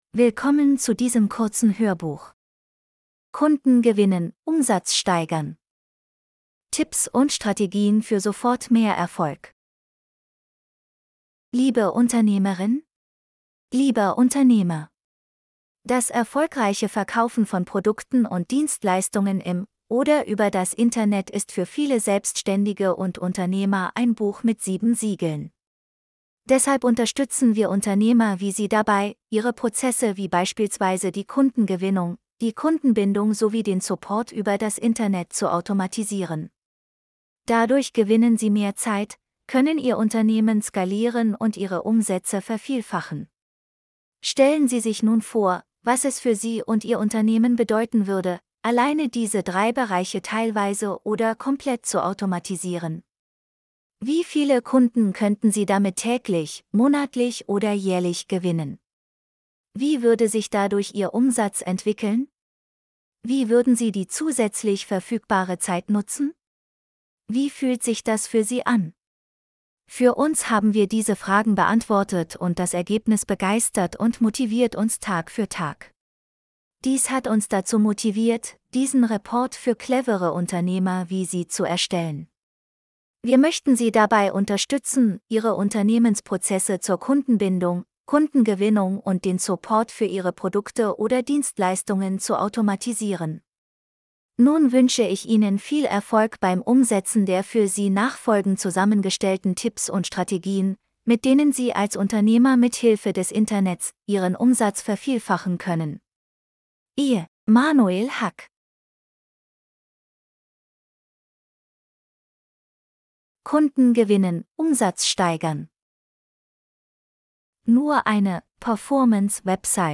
von ihp media | Hörbuch mit Tipps und Strategien für sofort mehr Erfolg